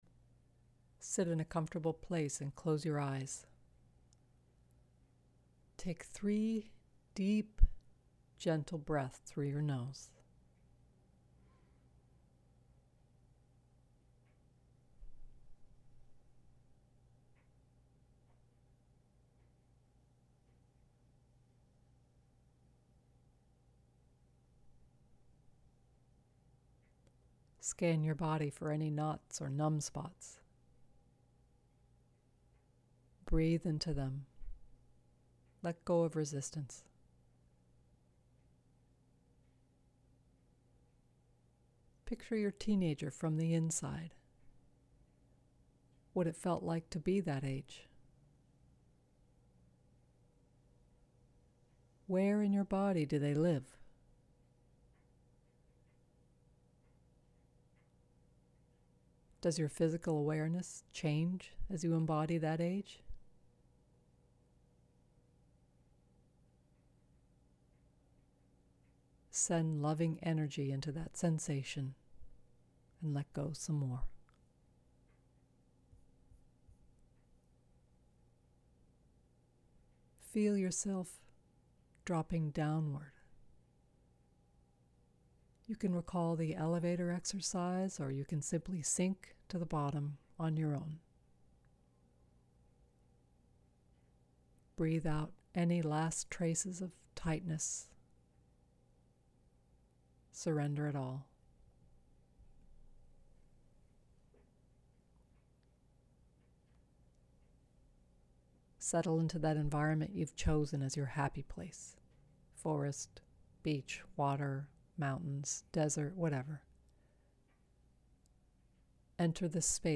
Guided visualizations